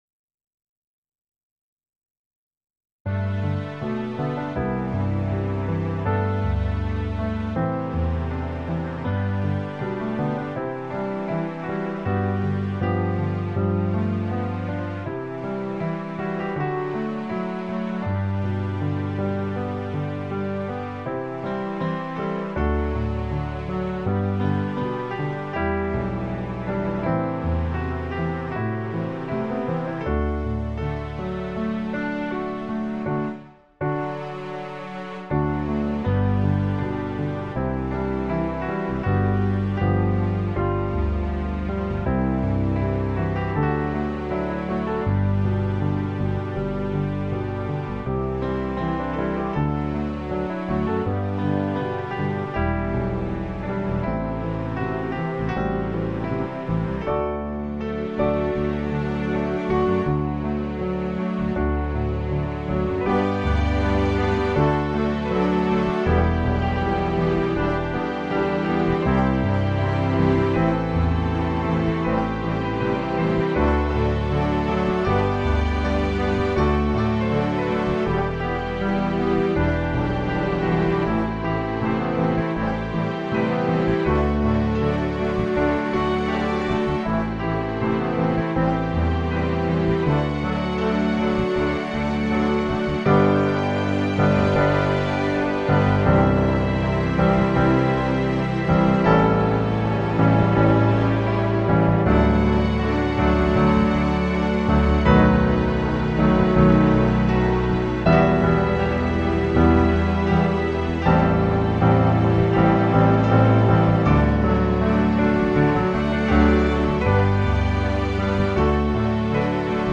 Accompaniment (WAV) (instrumental, no voices)